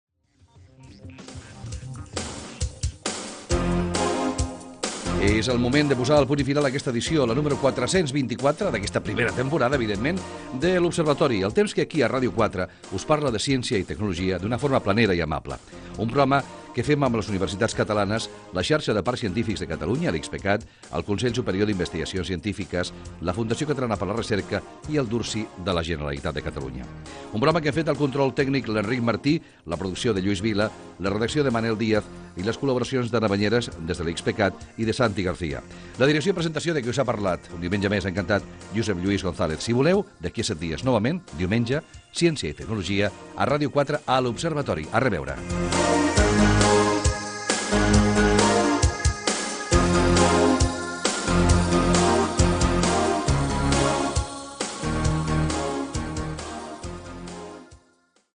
Comiat del programa, amb els noms de les entitats que hi col·laboren i de l'equip.
Gènere radiofònic
Divulgació